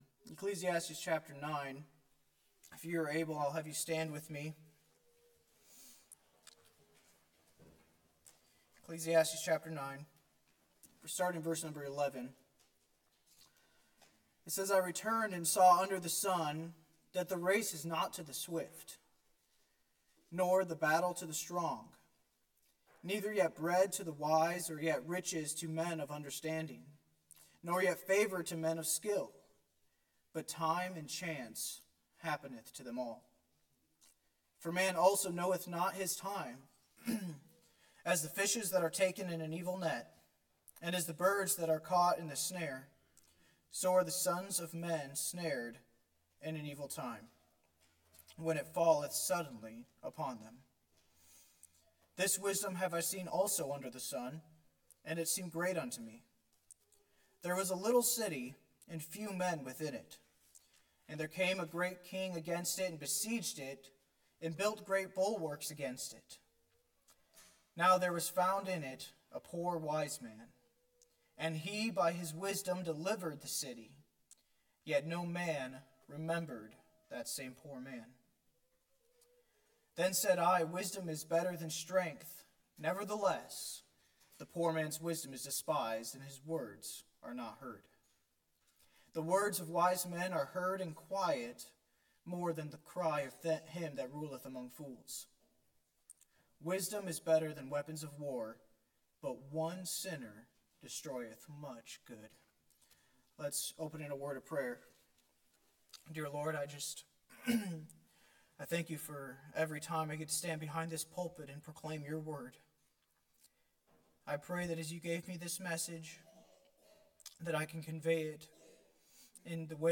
from the pulpit of Bethlehem Baptist Church in Viroqua, WI.